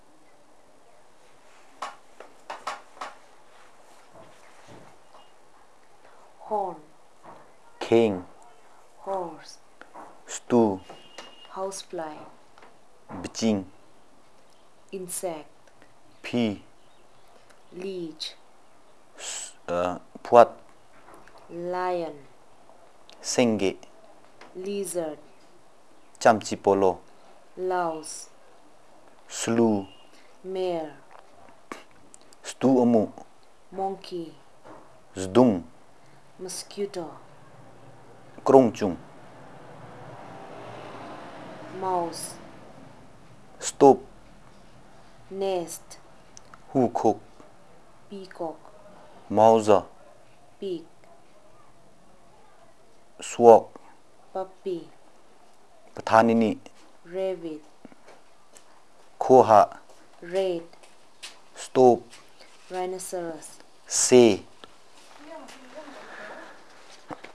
NotesThis is an elicitation of words about domestic animals, birds and related, insects and related and reptiles and rodents